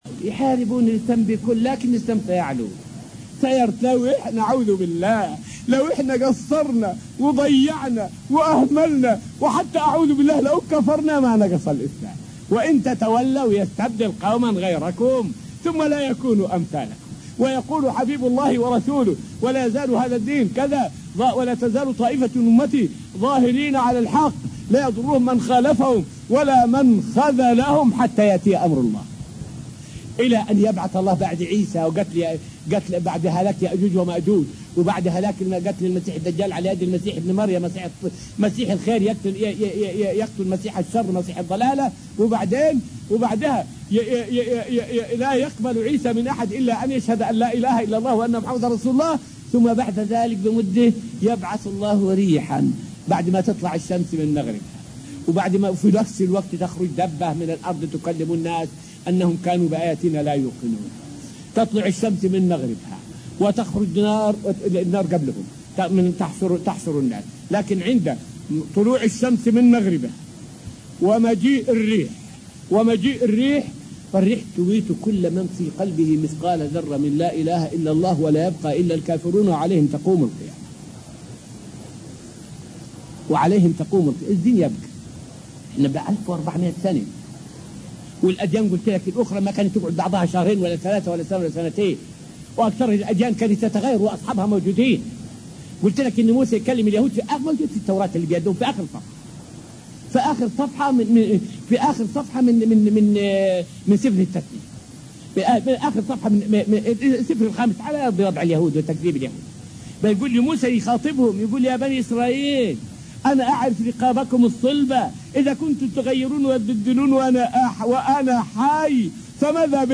فائدة من الدرس الثالث والعشرون من دروس تفسير سورة البقرة والتي ألقيت في المسجد النبوي الشريف حول دين الإسلام باق إلى قيام الساعة.